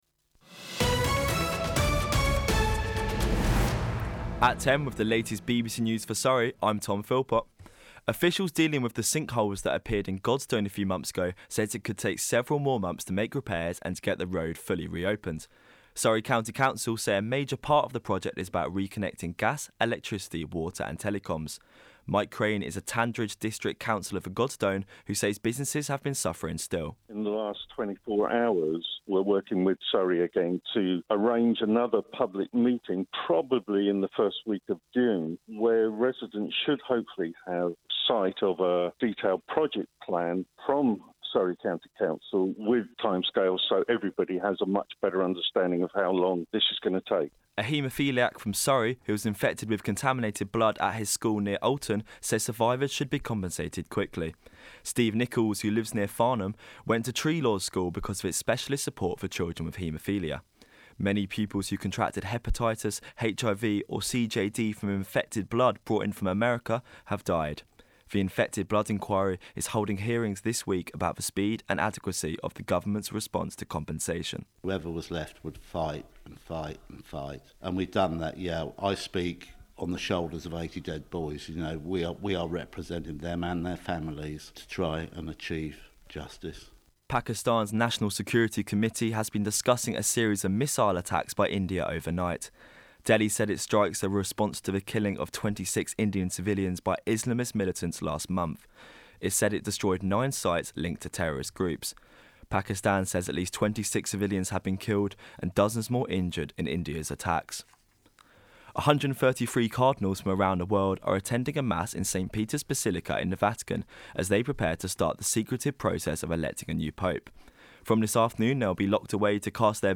Radio Portfolio
BBC Radio Surrey – News Broadcast